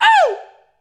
UH.wav